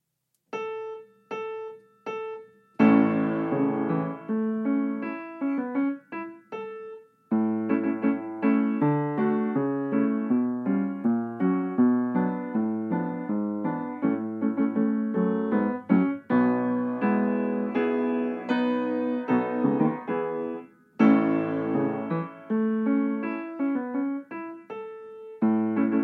Nagranie uwzględnia powtórki.
Zwolnienia uwzględnione.
Tempo 80 bmp
Nagranie dokonane na pianinie Yamaha P2, strój 440Hz
piano